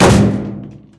barrel
hit_hard2.ogg